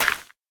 Minecraft Version Minecraft Version snapshot Latest Release | Latest Snapshot snapshot / assets / minecraft / sounds / block / suspicious_gravel / place2.ogg Compare With Compare With Latest Release | Latest Snapshot